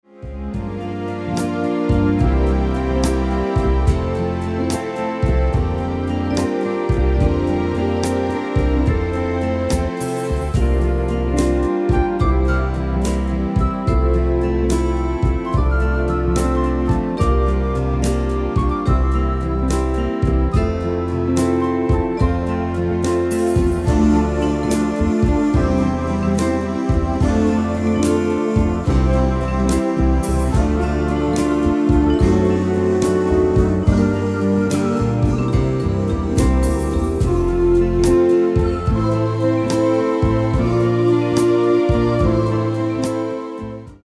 Demo/Koop midifile
Genre: Evergreens & oldies
Toonsoort: F
- Géén vocal harmony tracks